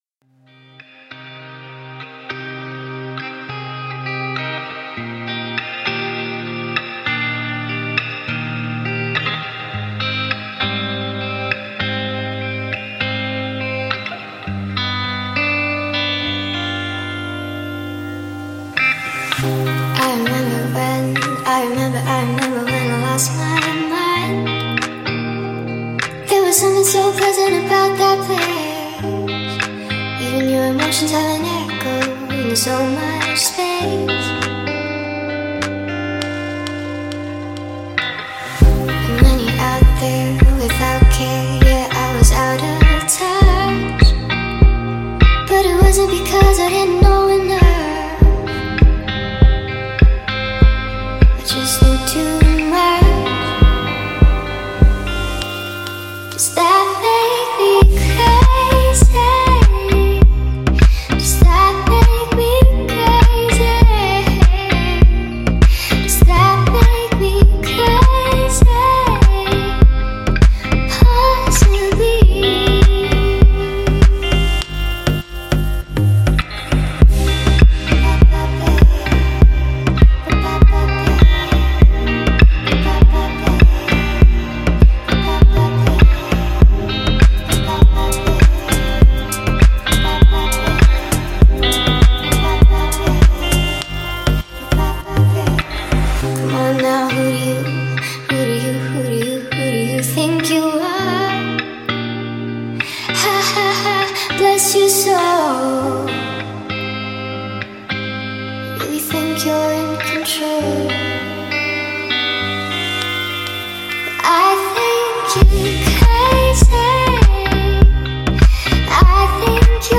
обладая нежным и мелодичным вокалом